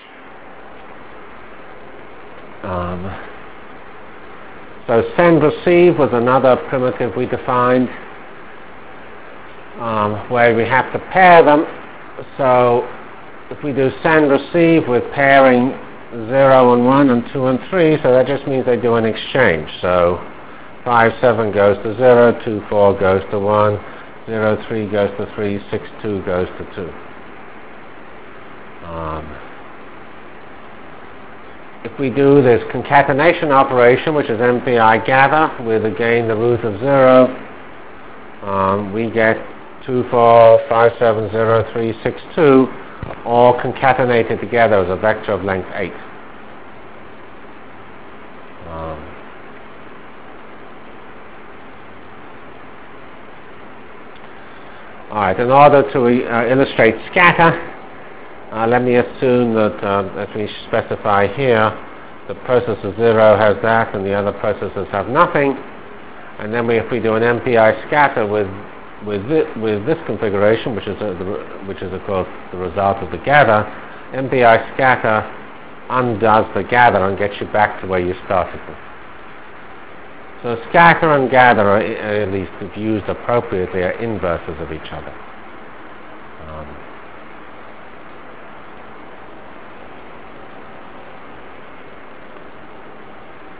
From CPS615-Completion of MPI foilset and Application to Jacobi Iteration in 2D Delivered Lectures of CPS615 Basic Simulation Track for Computational Science -- 7 November 96. by Geoffrey C. Fox